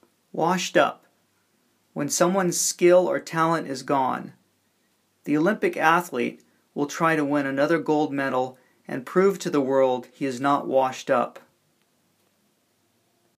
英語ネイティブによる発音は下記のリンクをクリックしてください 。